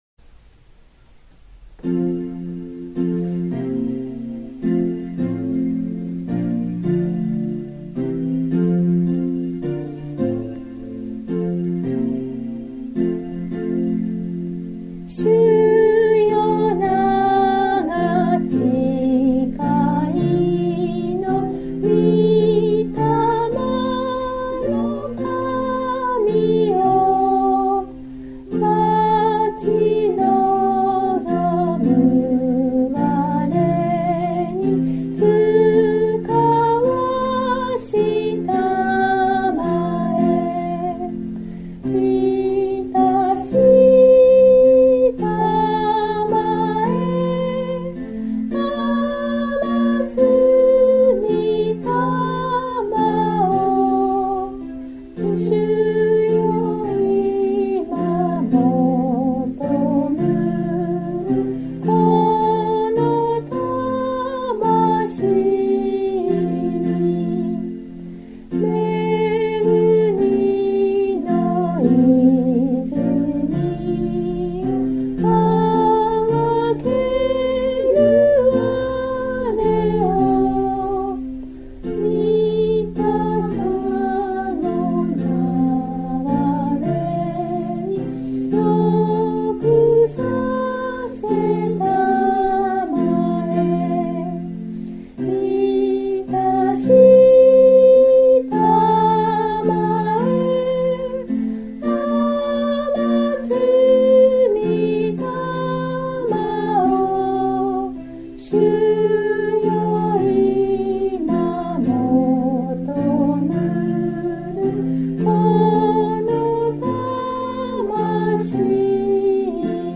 God bless you）より 唄
（徳島聖書キリスト集会集会員）